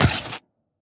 biter-roar-mid-3.ogg